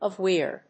音節avoir.